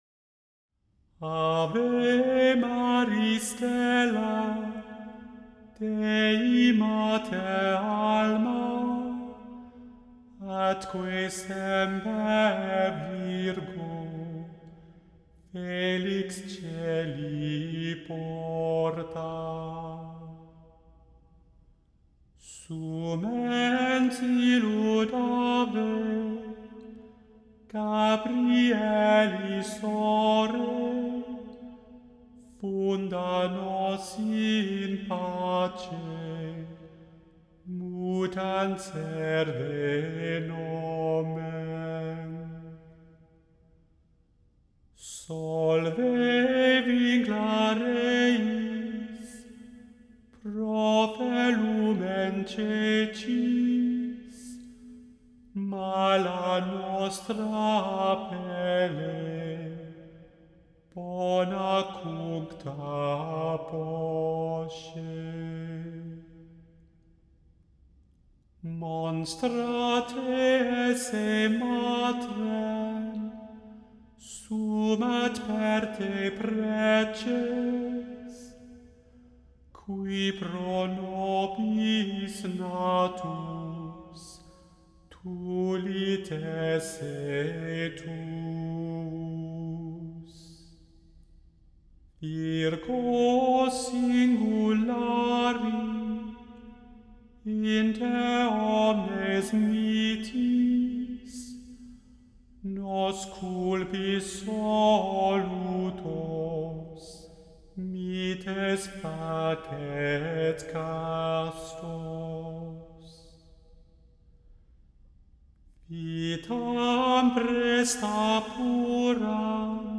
The Chant Project – Chant for Today (June 20) – Ave maris stella